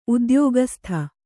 ಉದ್ಯೋಗಸ್ಥ ♪ udyōgastha noun (noun) a man engaged in a work, business or service. a male worker in a skilled trade; a craftsman; an artisan. a man hired by another or by a business firm, etc., to work for wages or salary; an employee.